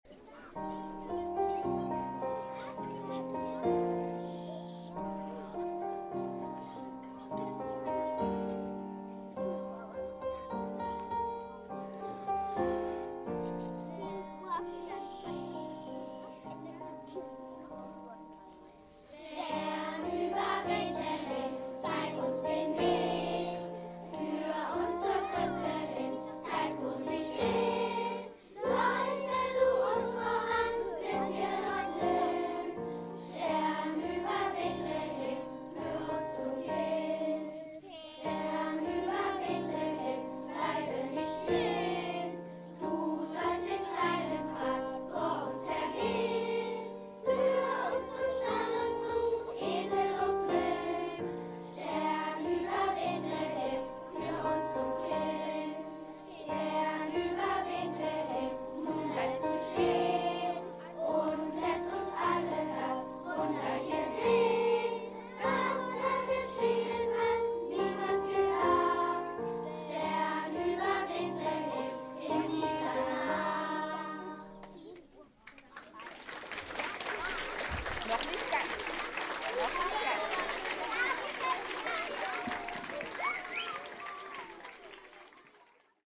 Weihnachtssingen des Schulchors der Ostschule
Der Auftritt wurde mitgeschnitten, sodass auch Eltern, die nicht zuhören konnten, sich die Lieder anhören können.
Schlagwörter: Schulchor, Weihnachtslieder♫ Posted in Schulchor der Ostschule | Ein Kommentar »